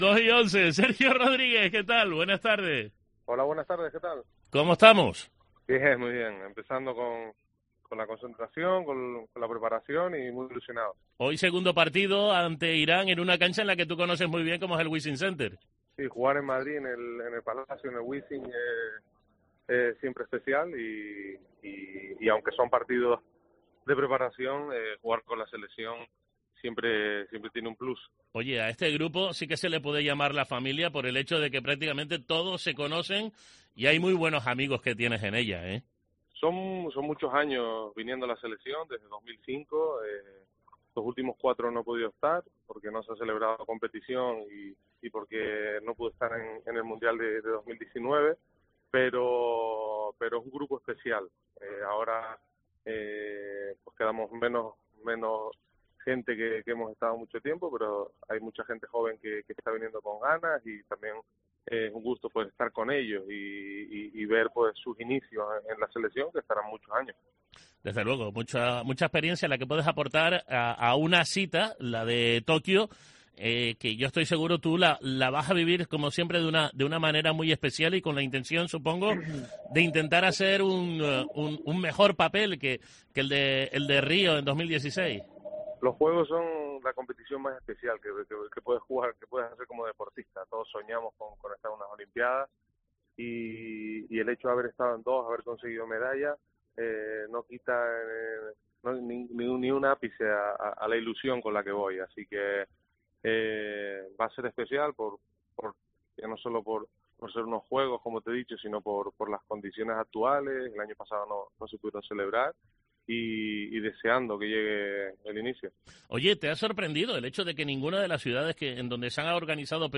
Entrevista a Sergio 'El Chacho' Rodríguez